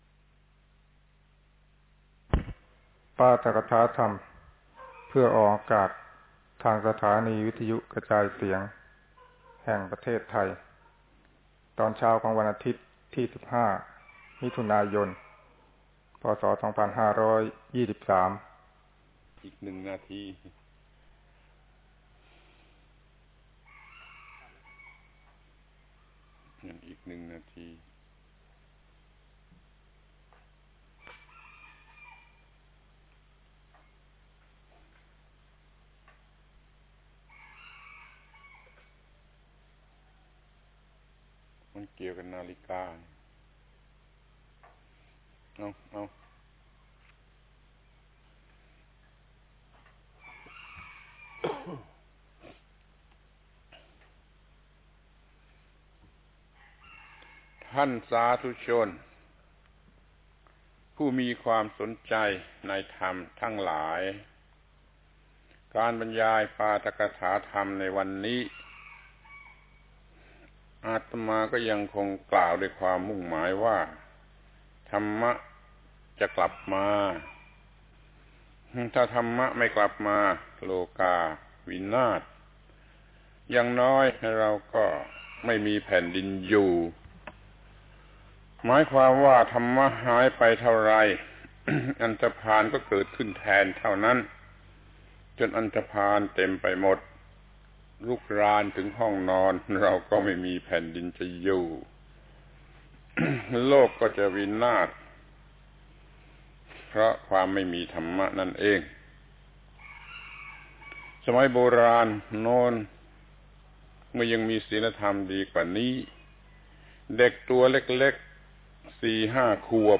ปาฐกถาธรรมออกอากาศวิทยุประเทศไทย ศีลธรรมกลับมา ครั้งที่ 24 ธรรมะในฐานะสิ่งที่ต้องความถูกฝาถูกตัว